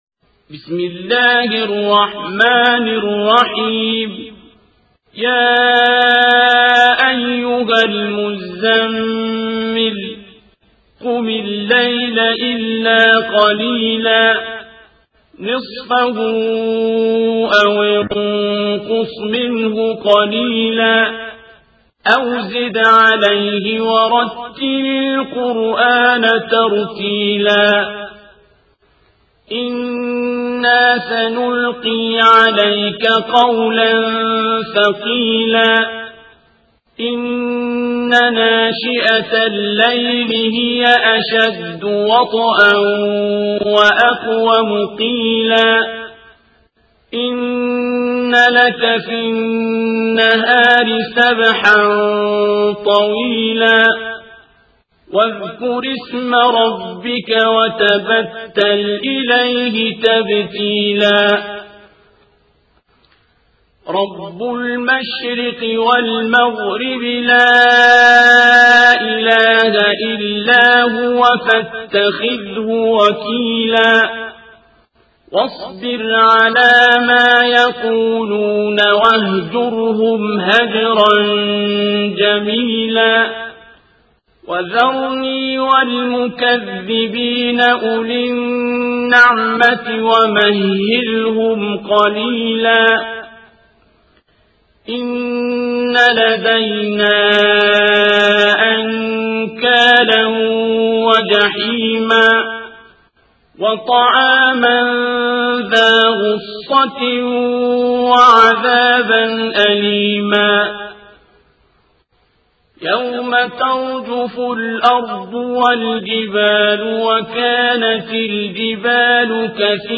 القارئ: الشيخ عبدالباسط عبدالصمد
تفاصيل : القرآن الكريم - سورة المزمل - الشيخ عبدالباسط عبدالصمد